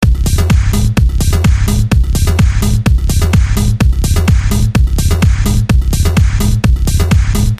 描述：最小的技术循环节拍集，带有工业化的色彩......请欣赏
Tag: 127 bpm Techno Loops Drum Loops 1.27 MB wav Key : Unknown